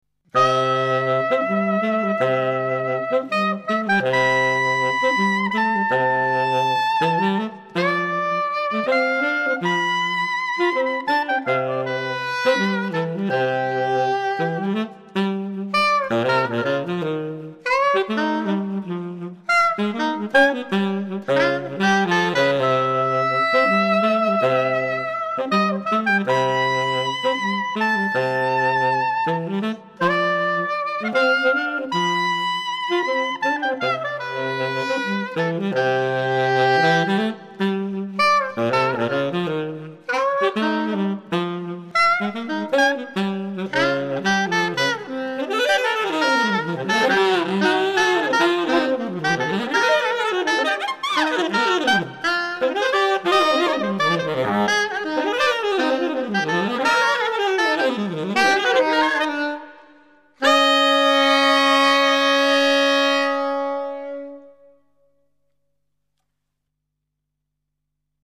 SAXOPHONE DUO